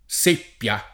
seppia [ S% pp L a ] s. f.